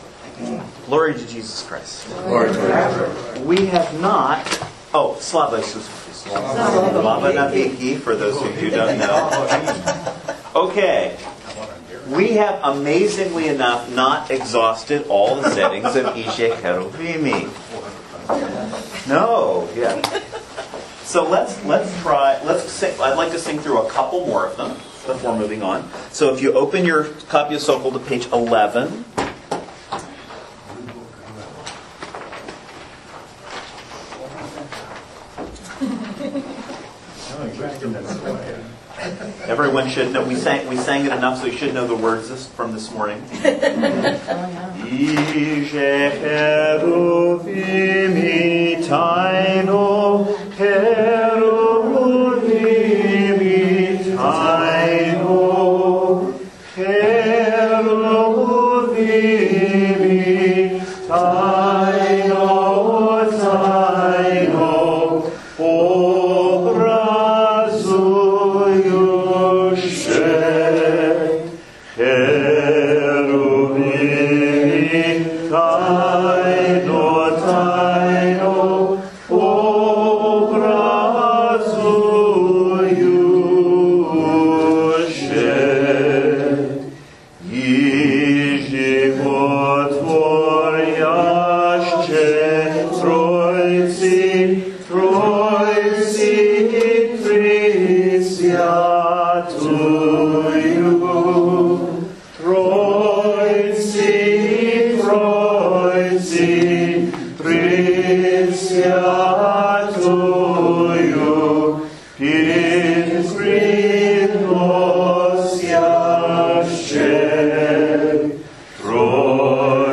Divine_Liturgy_in_Slavonic_2012_part_2.mp3